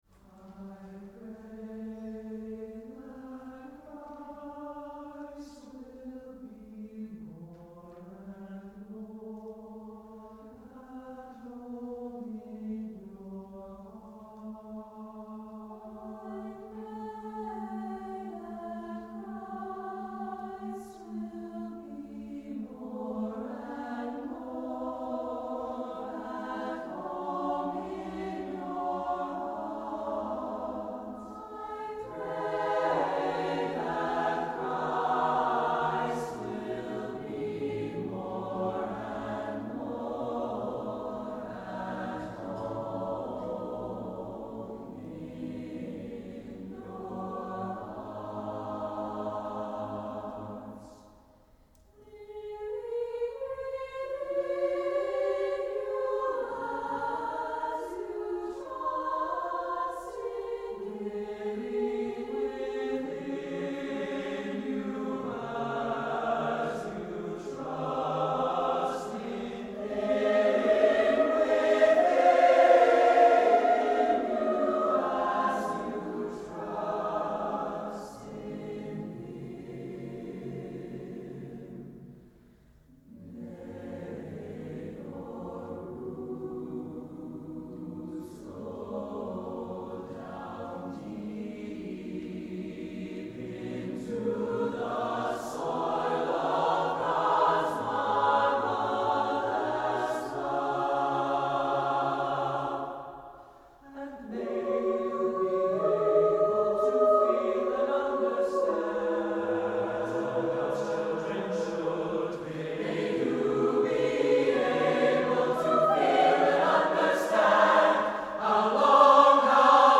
Compositions
It starts very softly, then gradually grows.
It isn’t compressed.   For SSAATTBB mixed choir